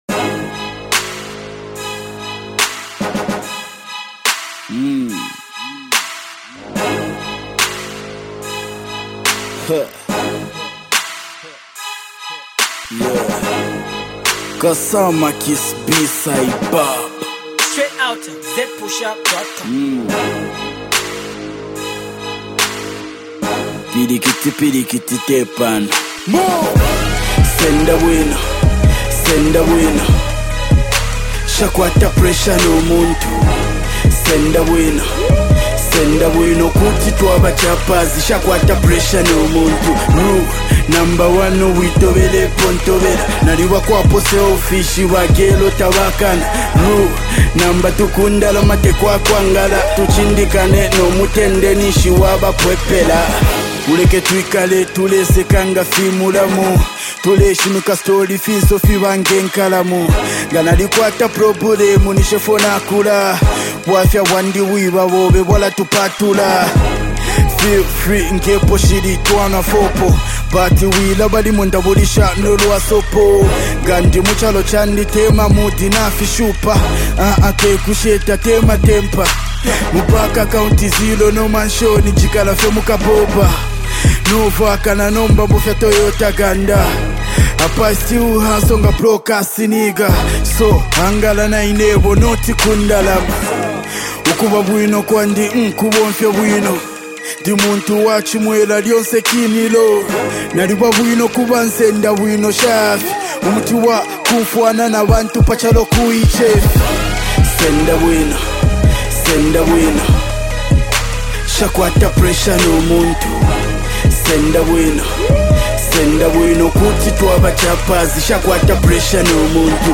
hot hip-hop joint
Get it below and enjoy real hip-hop.